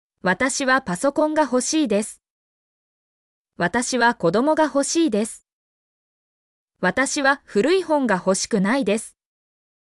mp3-output-ttsfreedotcom-98_HbKrzT7c.mp3